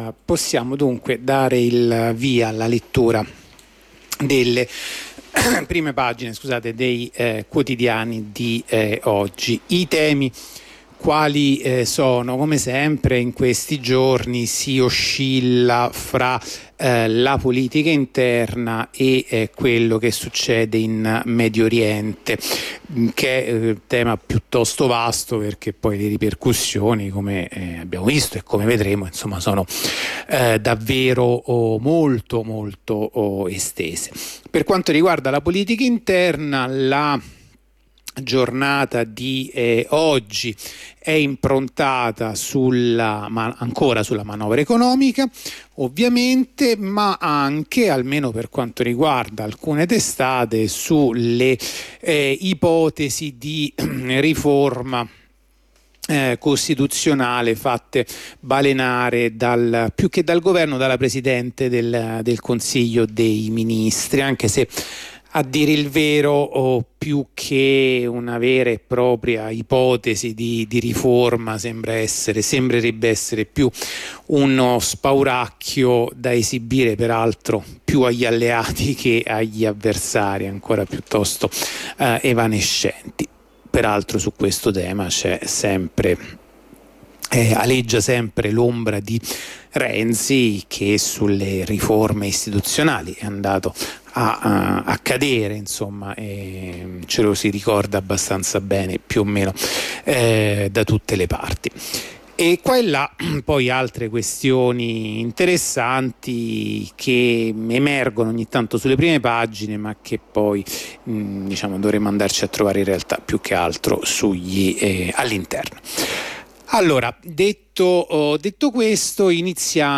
La rassegna stampa di radio onda rossa andata in onda martedì 31 ottobre 2023.